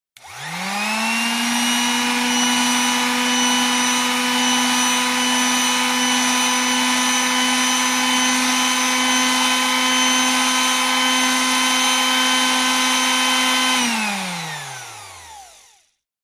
Orbital sander operating at variable speeds. Tools, Hand Sander, Tool Motor, Sander